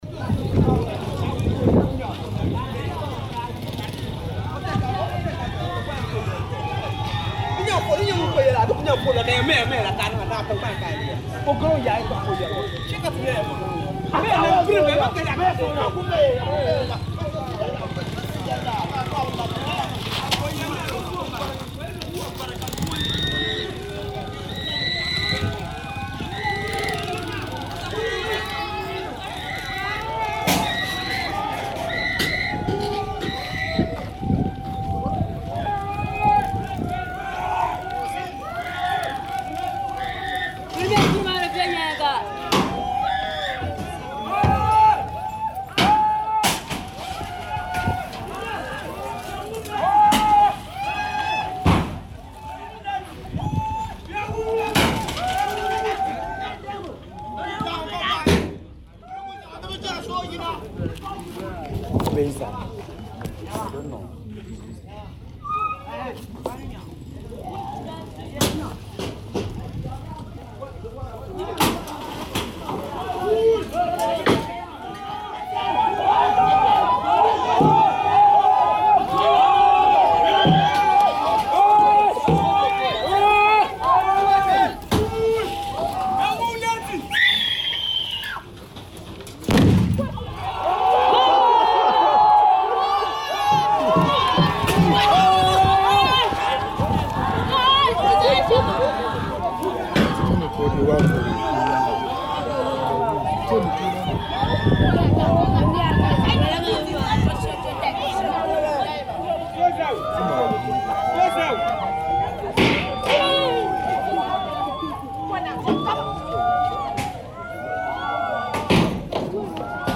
A member of the mob explained to Adom News why they had wanted to attack the woman.